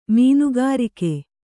♪ mīnugarike